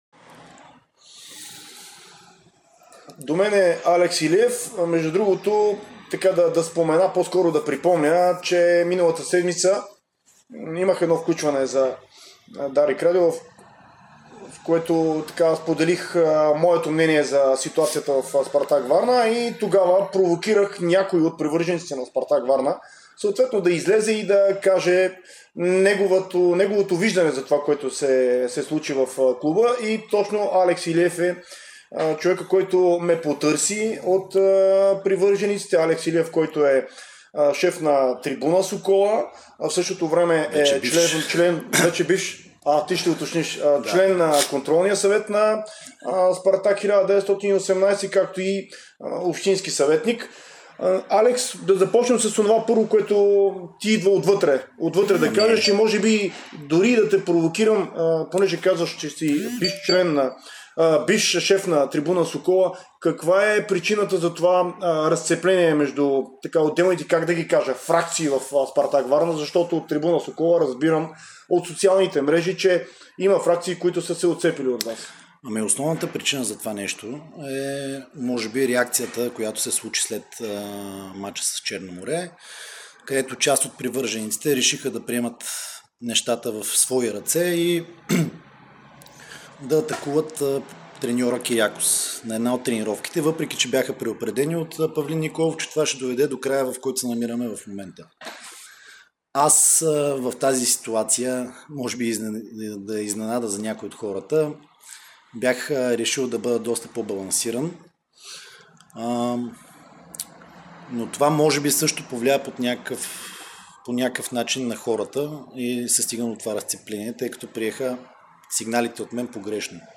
Цялата деструктивна ситуация даде възможност да се стигне до такова разцепление“, коментира той в специално интервю за Дарик радио и Dsport.